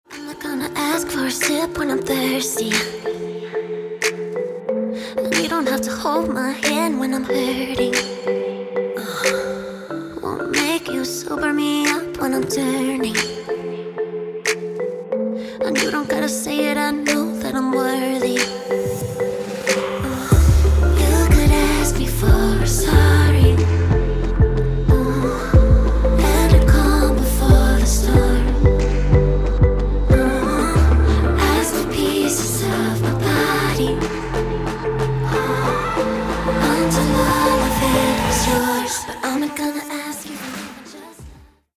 • Качество: 192, Stereo
поп
женский вокал
romantic
vocal